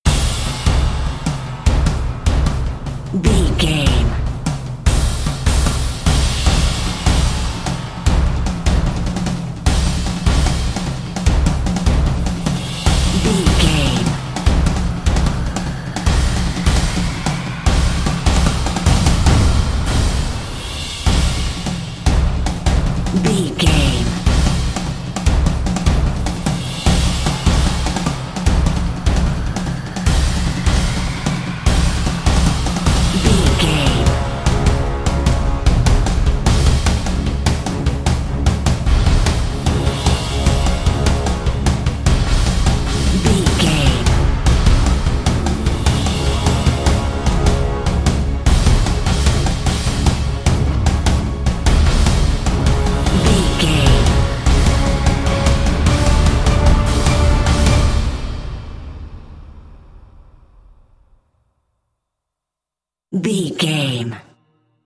Fast paced
In-crescendo
Aeolian/Minor
Fast
percussion
driving drum beat